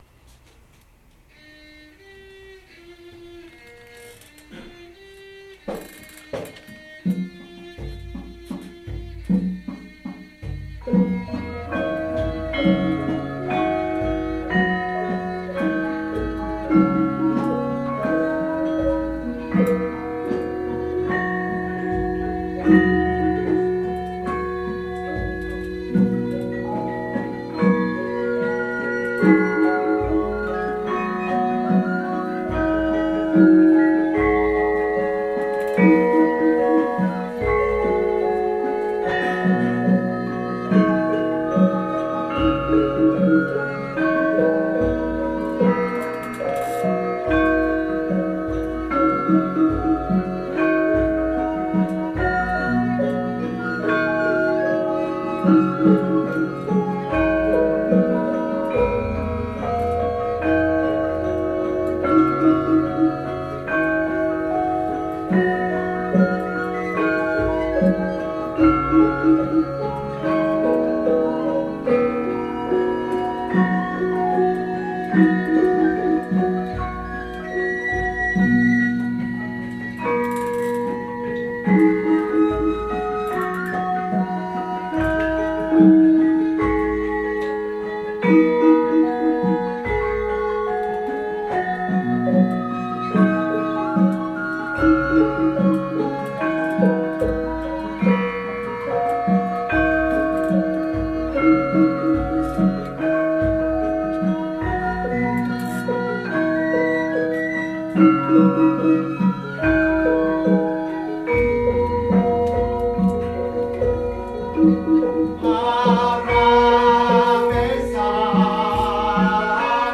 Group:  Gamelan Nyai Saraswati
Chapel Hill Museum
These recordings were captured through the wonders of mini-disc technology.
Ladrang Wilujeng, Laras Slendro, Pathet Manyura